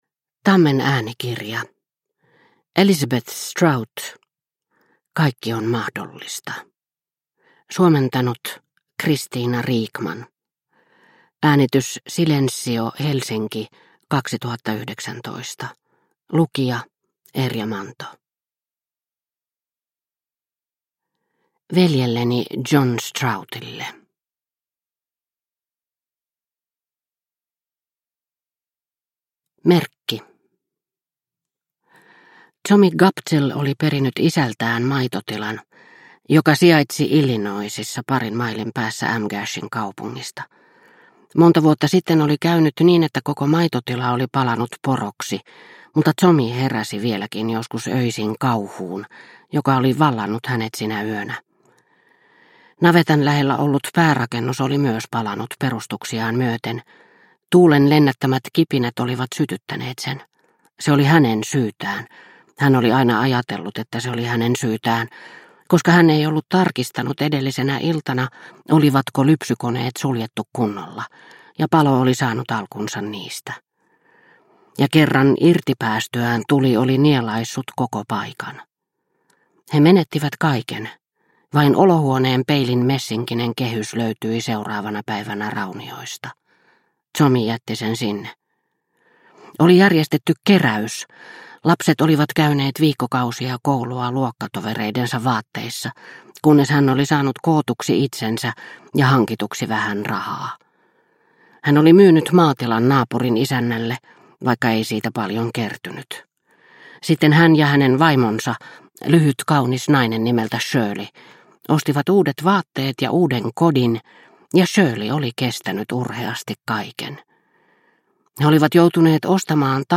Kaikki on mahdollista – Ljudbok – Laddas ner